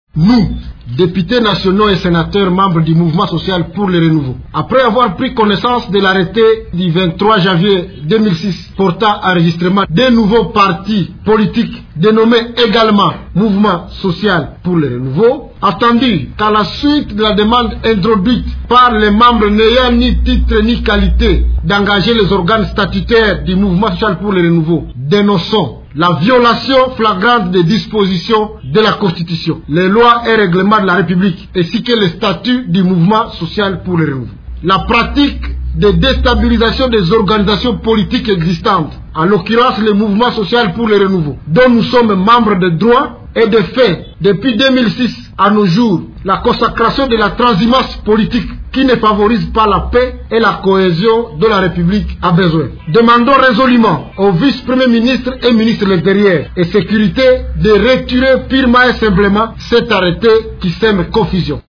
Vous pouvez écouter un extrait de la déclaration de ces parlementaires lue par le député Muhindo Nzanga, président du groupe parlementaire MSR.